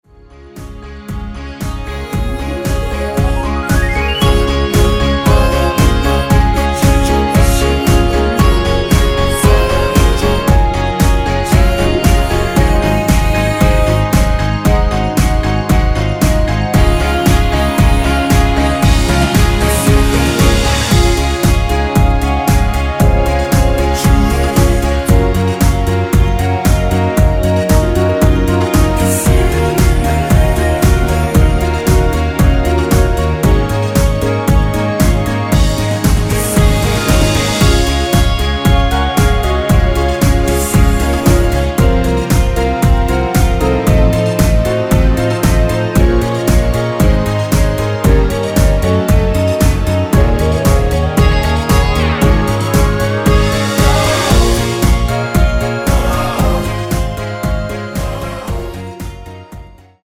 엔딩이 페이드 아웃이라 노래 부르기 좋게 엔딩 만들었습니다.
원키에서(-2)내린 멜로디와 코러스 포함된 MR입니다.
Db
앞부분30초, 뒷부분30초씩 편집해서 올려 드리고 있습니다.
(멜로디 MR)은 가이드 멜로디가 포함된 MR 입니다.